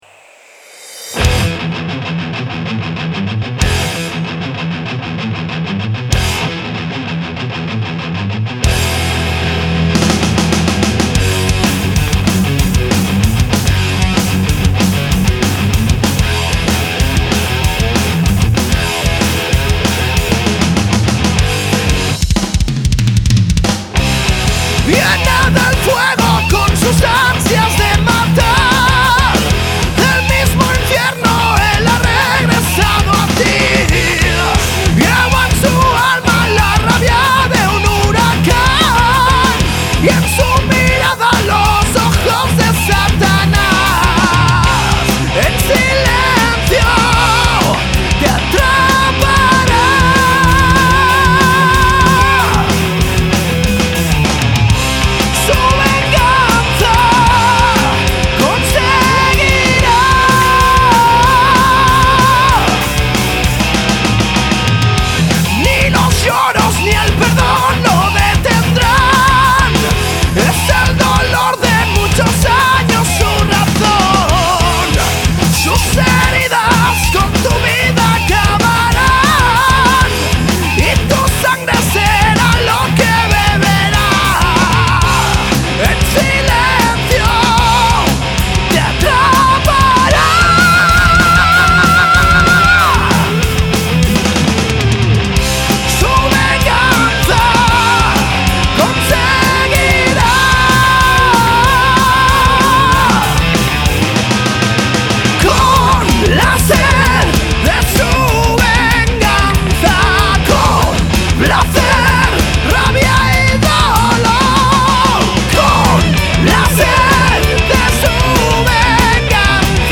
Voz
Guitarra
Bajo
Batería